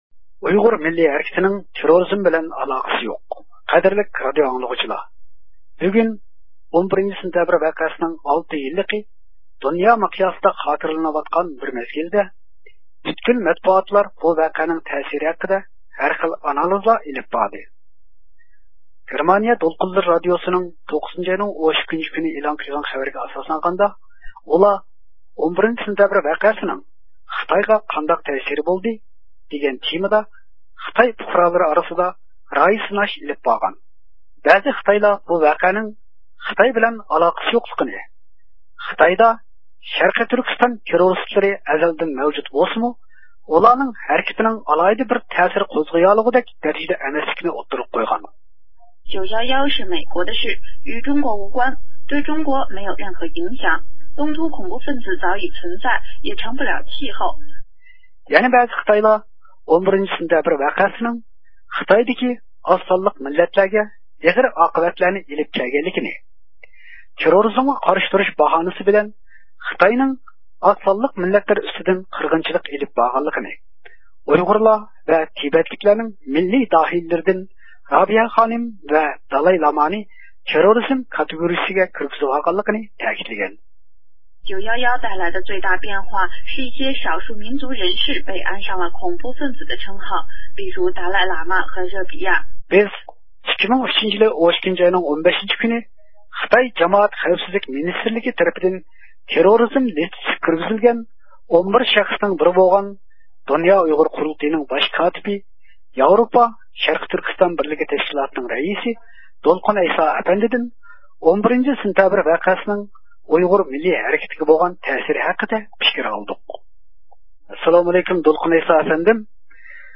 بىز 2003 – يىلى 12 – ئاينىڭ 15 – كۈنى خىتاي جامائەت خەۋپسىزلىك مىنىستىرلىقى تەرىپىدىن تېررورچى تىزىملىكىگە كىرگۈزۈلگەن 11 شەخىسنىڭ بىرى بولغان د ئۇ ق نىڭ باش كاتىپى، ياۋرۇپا شەرقىي تۈركىستان بىرلىكى تەشكىلاتىنىڭ رەئىسى دولقۇن ئەيسا ئەپەندى بىلەن، 11 – سېنتەبىر ۋەقەسىنىڭ ئۇيغۇر مىللىي ھەرىكىتىگە بولغان تەسىرى ھەققىدە سۆھبەت ئېلىپ باردۇق.